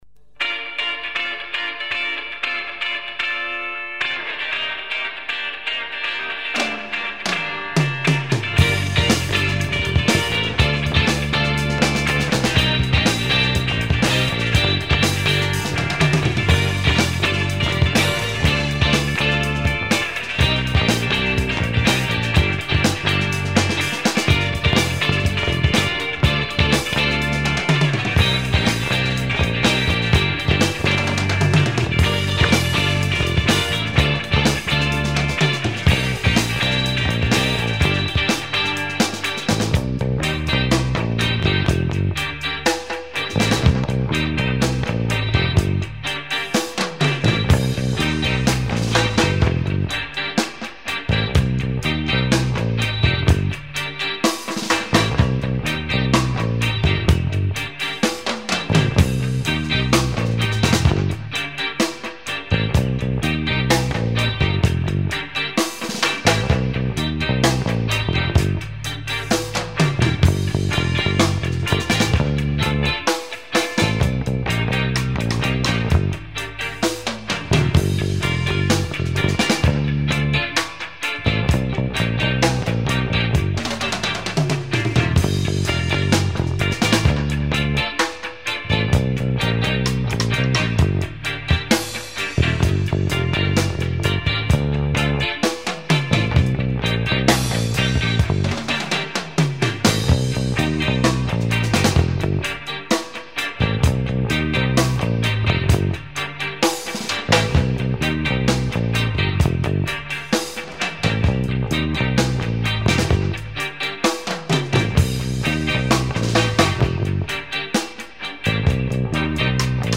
Drums
Guitar, Voice
Bass